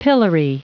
Prononciation du mot pillory en anglais (fichier audio)
Prononciation du mot : pillory